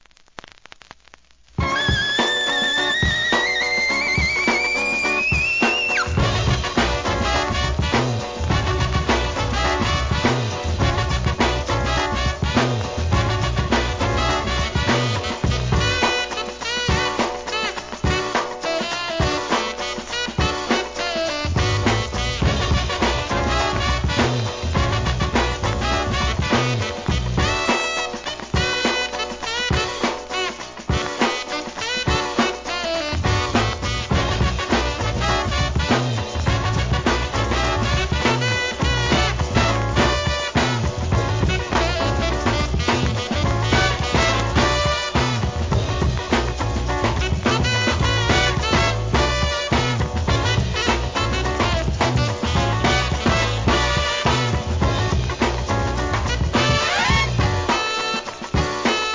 ¥ 1,540 税込 関連カテゴリ SOUL/FUNK/etc...